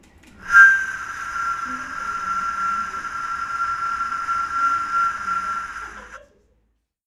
2011_바람.mp3